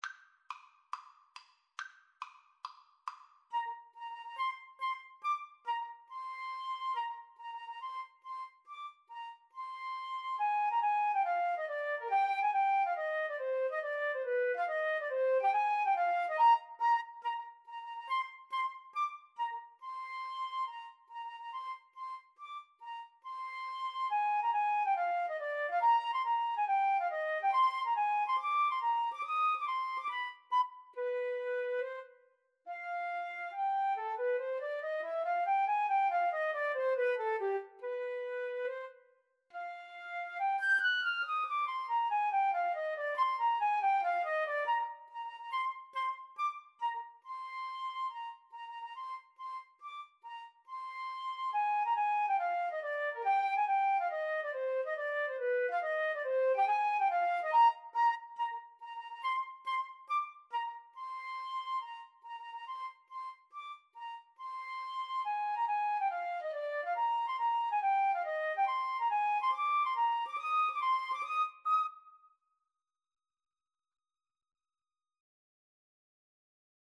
Tempo di marcia =140